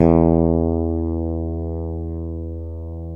Index of /90_sSampleCDs/Roland L-CD701/BS _Jazz Bass/BS _Ch.Fretless